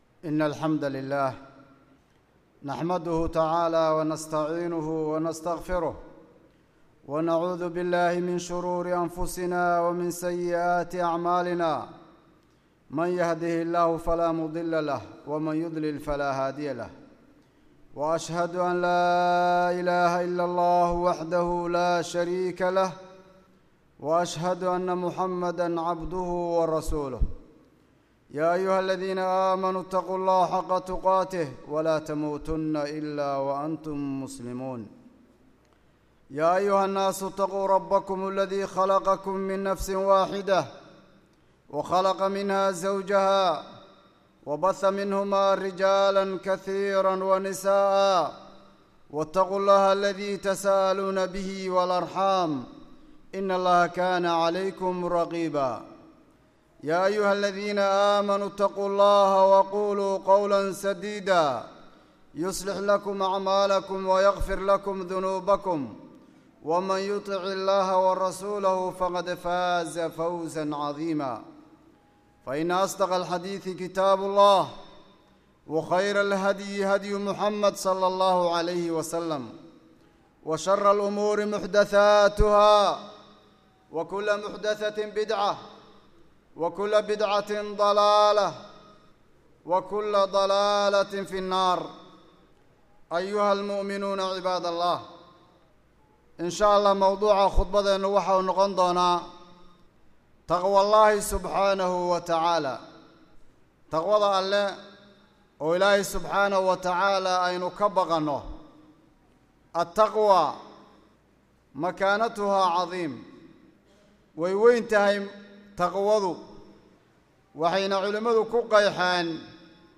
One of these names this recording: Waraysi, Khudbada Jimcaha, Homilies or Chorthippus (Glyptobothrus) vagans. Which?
Khudbada Jimcaha